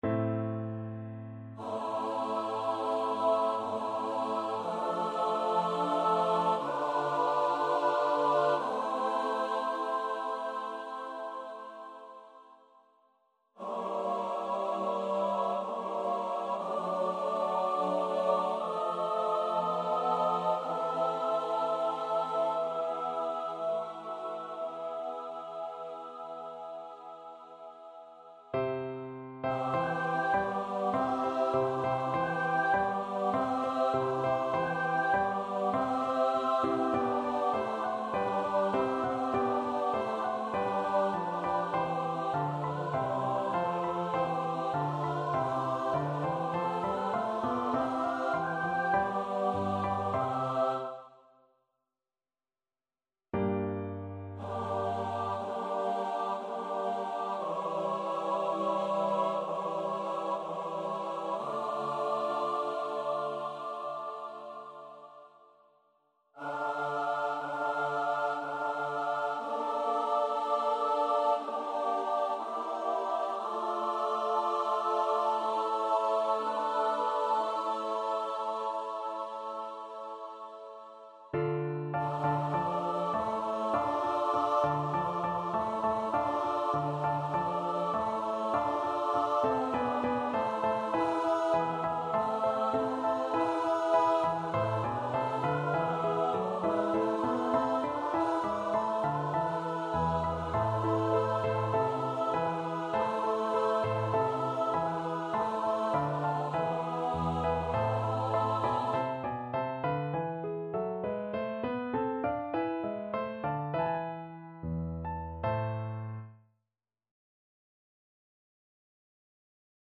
Choir  (View more Intermediate Choir Music)
Classical (View more Classical Choir Music)